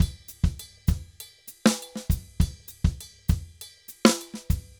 Drums_Salsa 100_3.wav